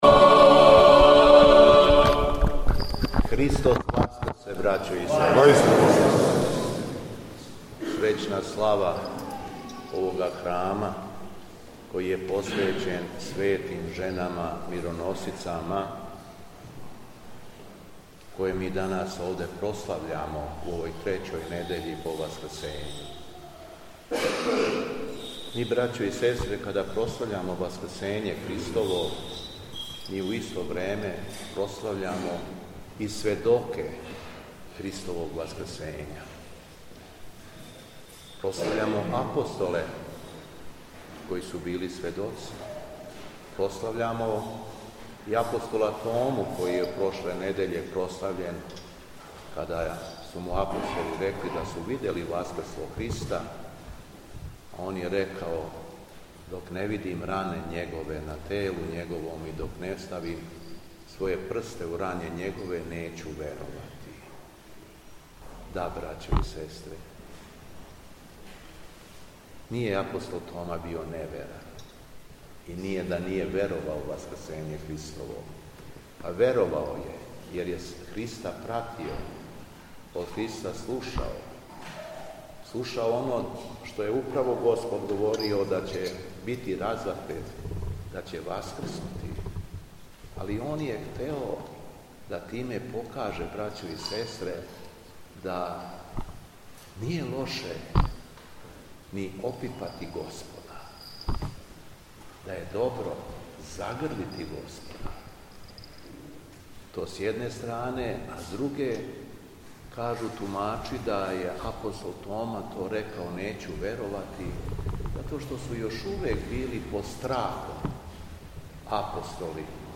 Беседа Његовог Високопреосвештенства Митрополита шумадијског г. Јована
Појали су ученици богословије Светог Јована Златоуста из Крагујевца.